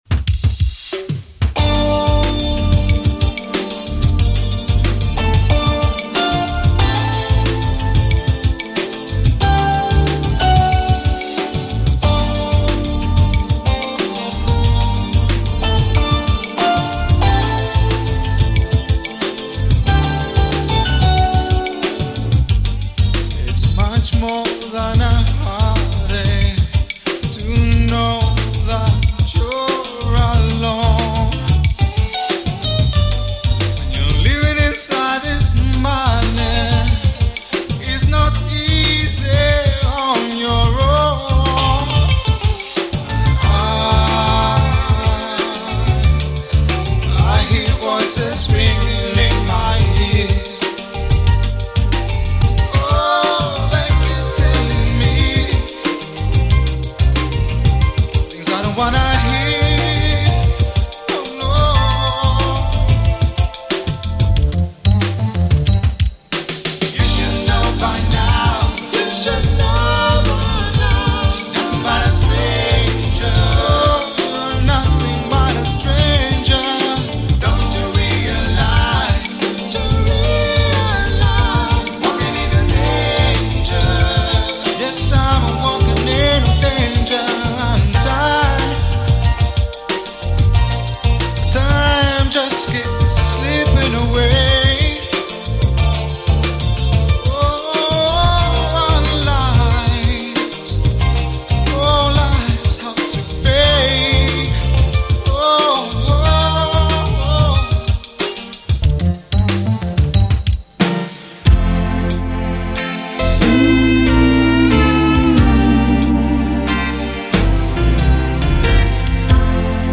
*Tune in now, but have a hankie at the ready...